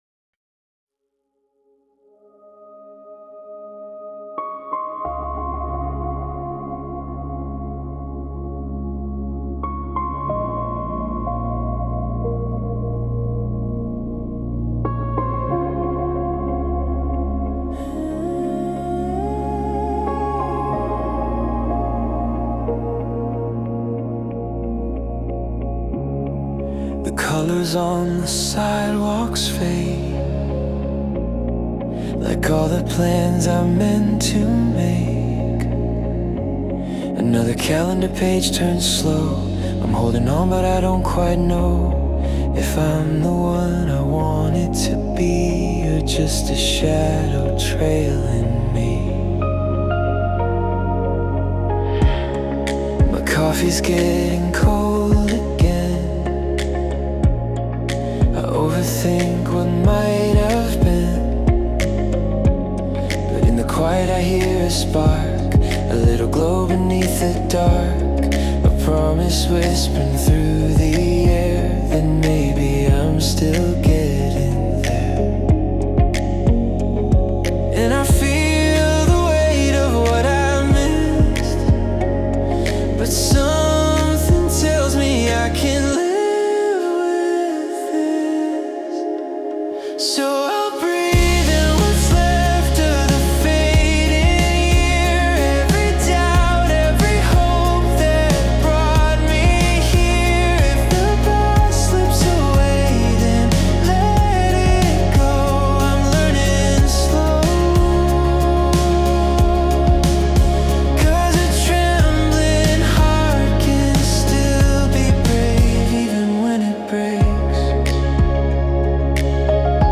洋楽男性ボーカル著作権フリーBGM ボーカル
男性ボーカル洋楽洋楽 男性ボーカルプロフィールムービーエンドロールポップスバラードシネマティック・映画音楽風切ない幻想的
男性ボーカル（洋楽・英語）曲です。